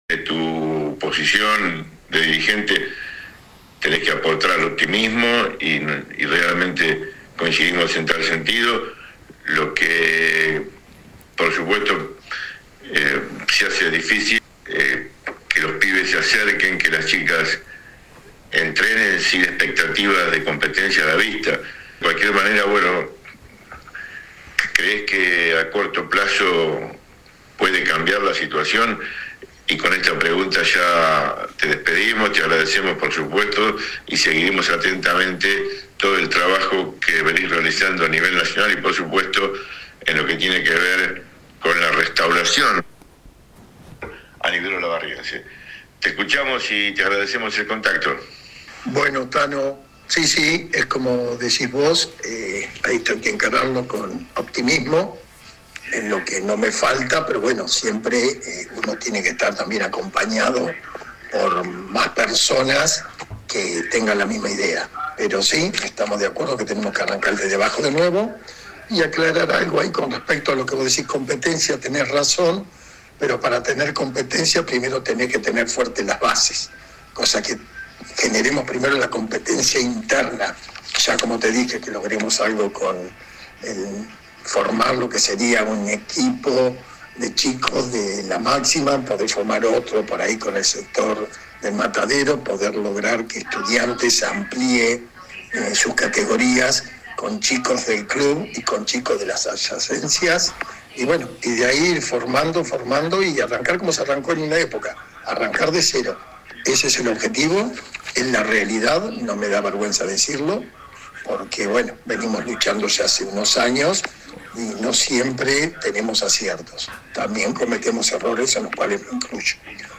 AUDIO DE LA ENTREVISTA ( en tres bloques )